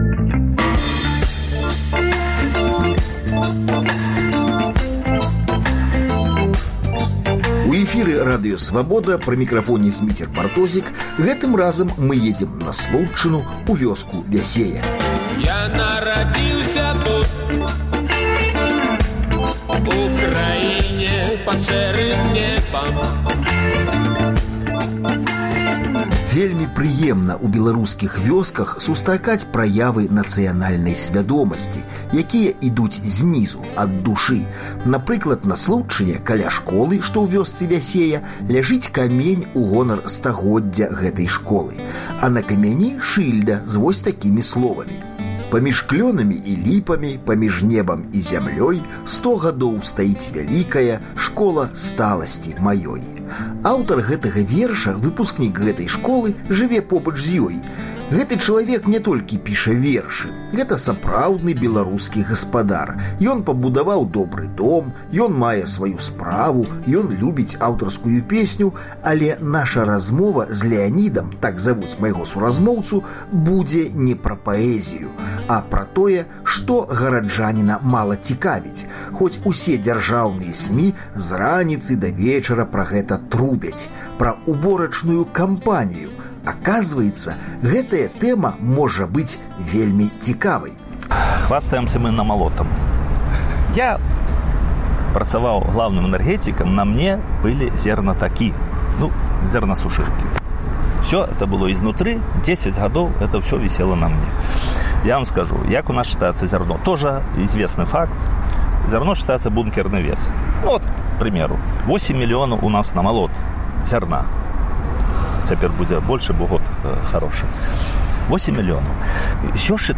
Дзеля чаго да гэтае пары ў сельскай гаспадарцы Беларусі захаваўся такі савецкі рудымент, як прыпіскі? Пра гэта пойдзе размова з былымі калгаснымі брыгадзірамі ў вёсцы Вясея, што на Случчыне.